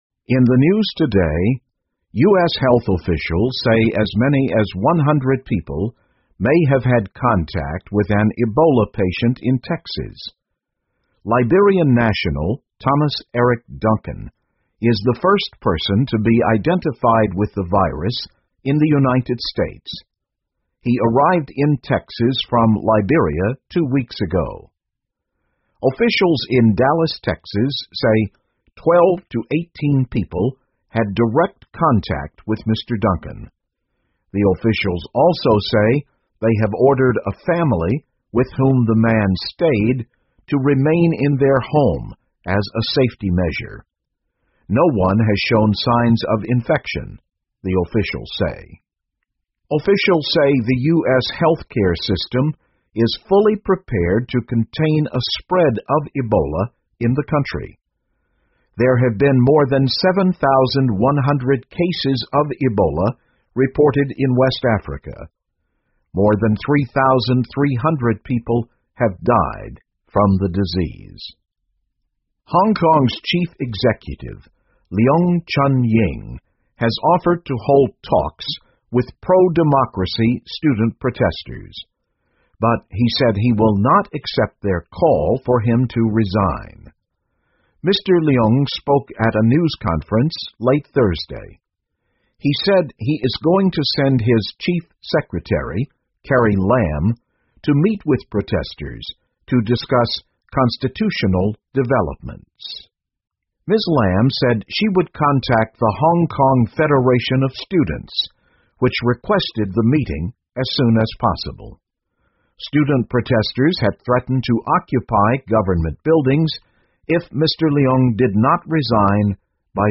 VOA慢速英语2014 美国官员称多达100人接触过埃博拉患者 听力文件下载—在线英语听力室